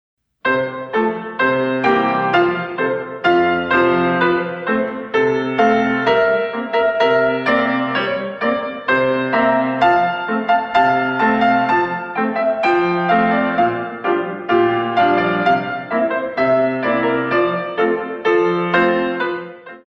In 2
48 Counts